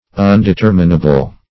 Undeterminable \Un`de*ter"mi*na*ble\, a.